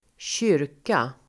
Uttal: [²tj'yr:ka]